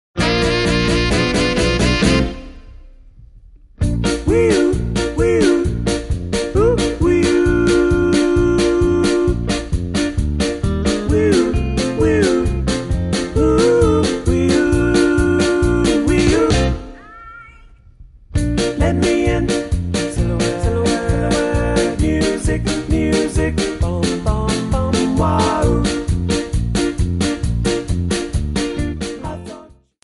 Bb
MPEG 1 Layer 3 (Stereo)
Backing track Karaoke
Pop, Oldies, 1960s